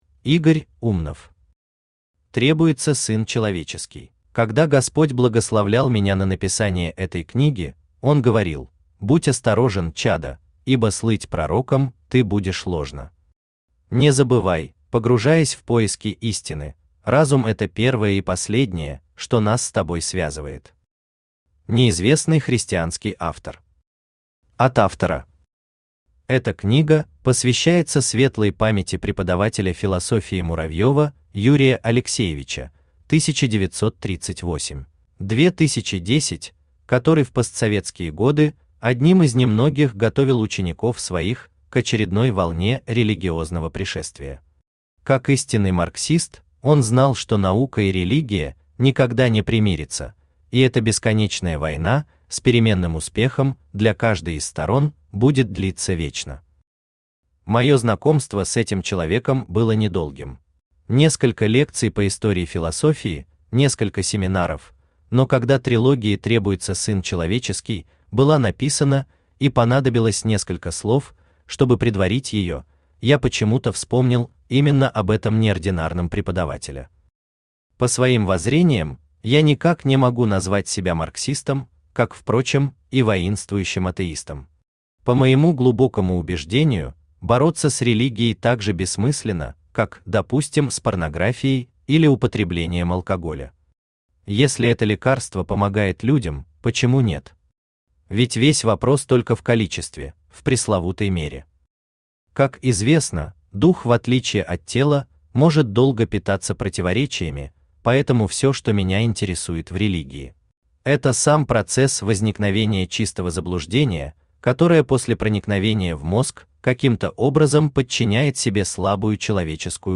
Аудиокнига Требуется Сын Человеческий | Библиотека аудиокниг
Aудиокнига Требуется Сын Человеческий Автор Игорь Викторович Умнов Читает аудиокнигу Авточтец ЛитРес.